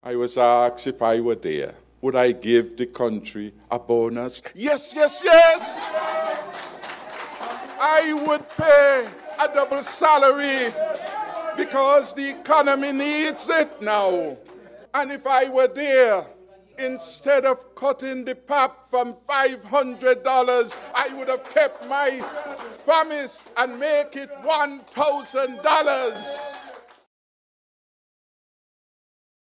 Commenting on the topic, the People’s Labour Party (PLP’s) Leader and former Prime Minister, Dr. Timothy Harris stated during the Party’s press conference late last month: